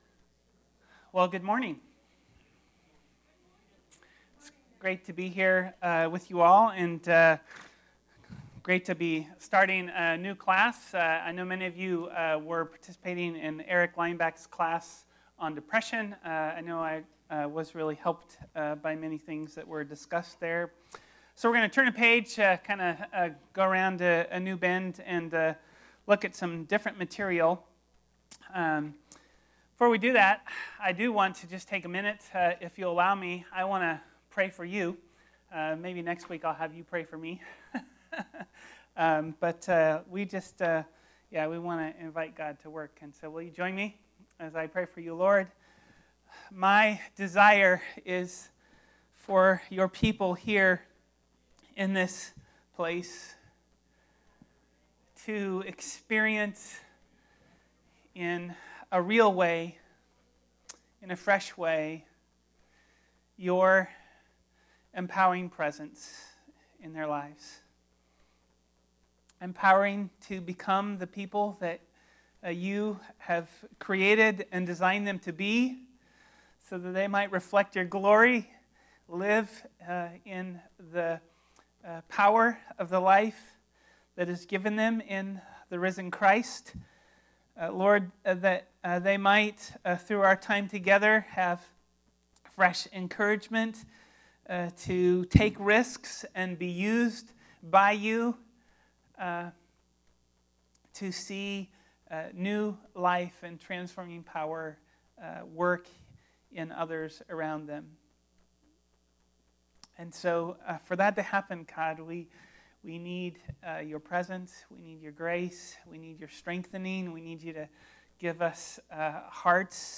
Type: Sunday School